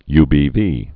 (ybē-vē)